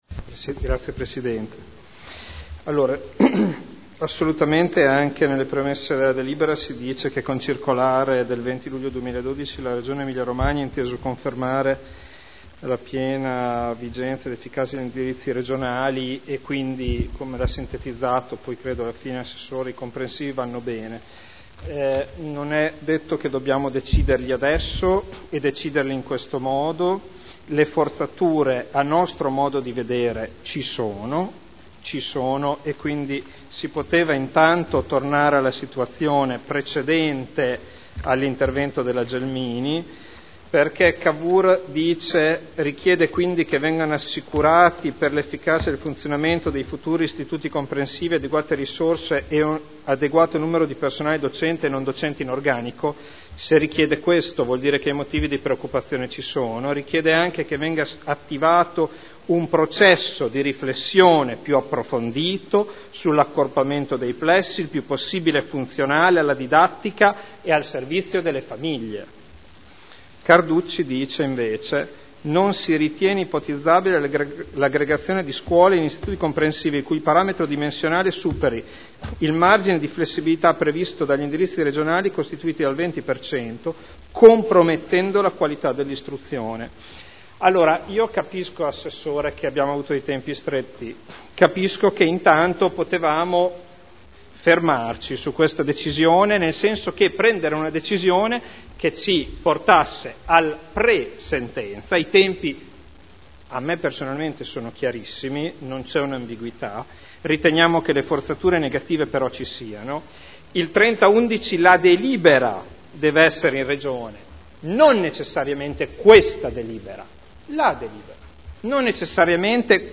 Seduta del 26/11/2012 Dichiarazione di voto.